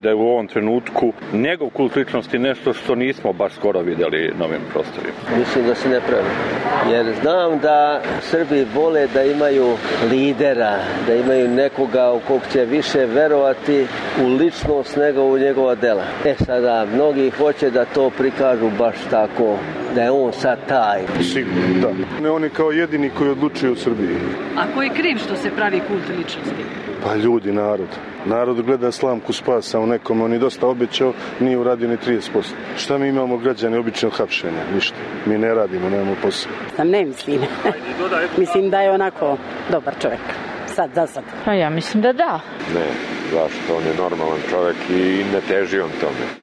Građani o Vučiću